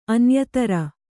♪ anyatara